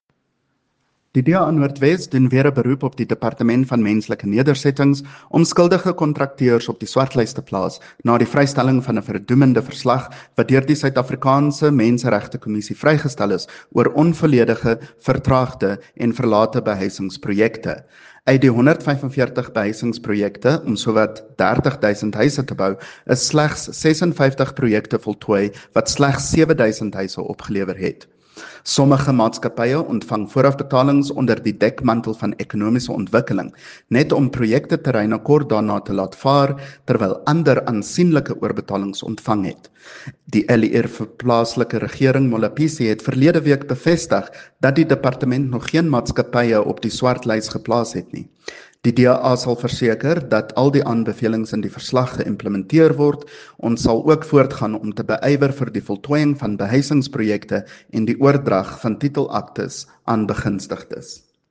Afrikaans by CJ Steyl MPL